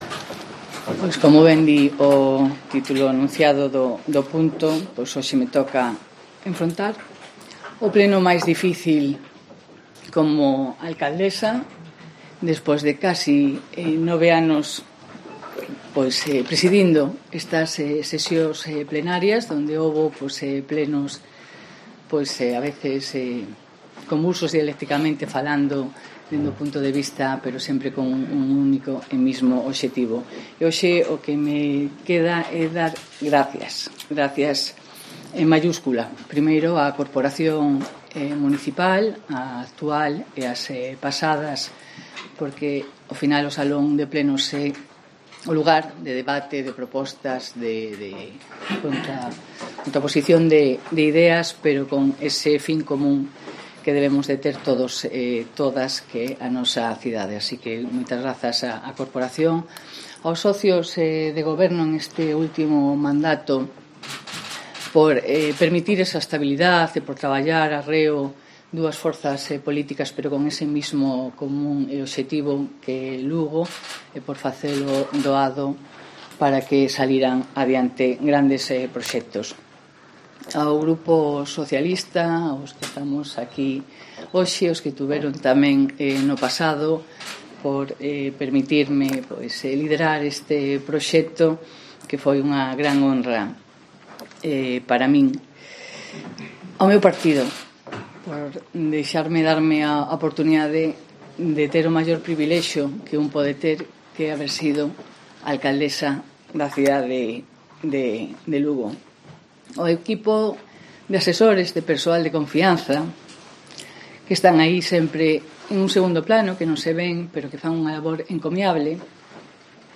Intervención de la alcaldesa de Lugo durante el pleno extraordinario en el que presentó su renuncia